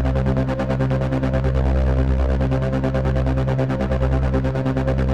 Index of /musicradar/dystopian-drone-samples/Tempo Loops/140bpm
DD_TempoDroneA_140-C.wav